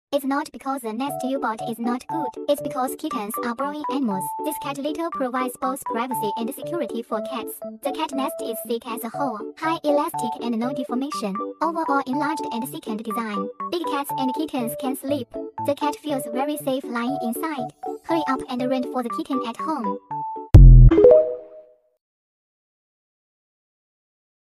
Fluffy Warm Cat Bed, Semi Encl sound effects free download